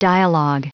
Prononciation du mot dialog en anglais (fichier audio)
Prononciation du mot : dialog